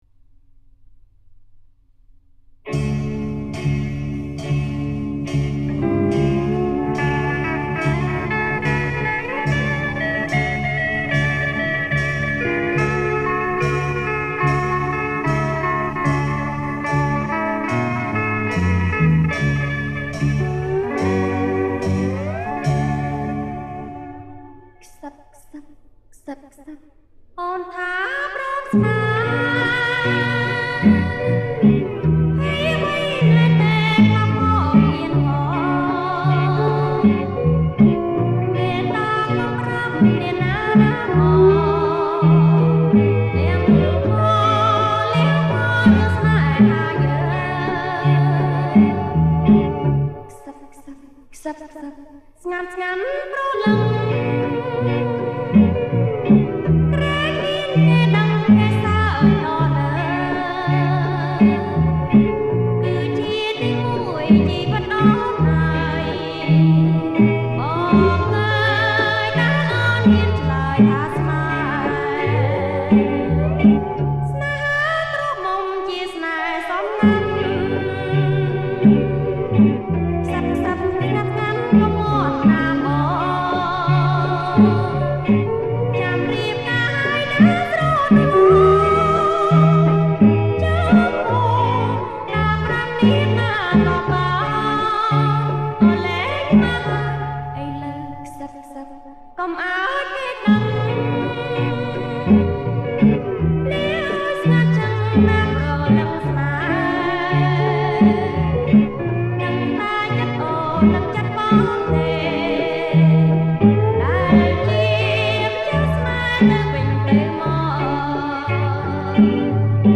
ប្រគំជាចង្វាក់